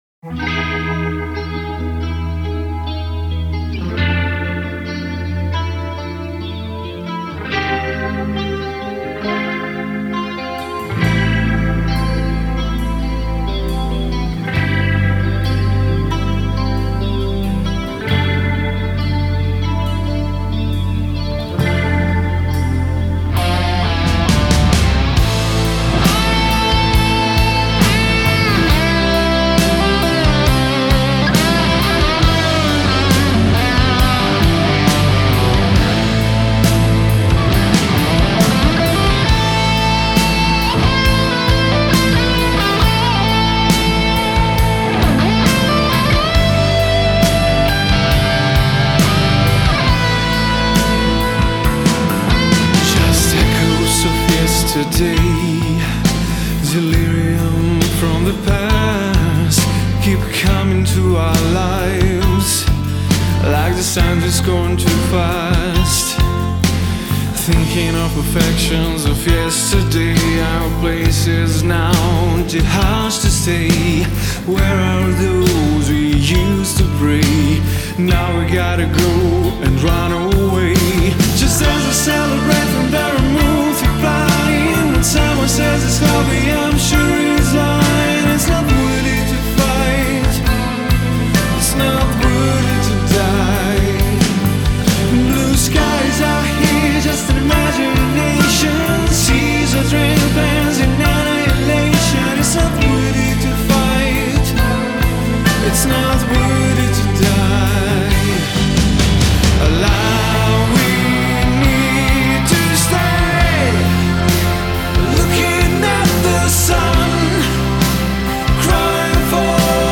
psychedelic-hard-rocka
kvartet
floydovski slojevite
gitaru
bas gitarist
bubnjar